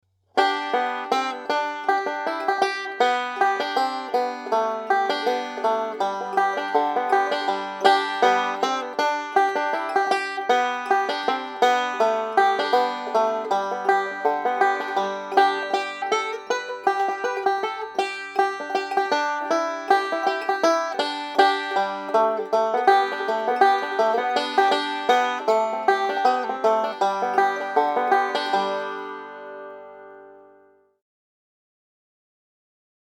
DIGITAL SHEET MUSIC - 5-STRING BANJO SOLO
Three-finger "Scruggs style"
learning speed and performing speed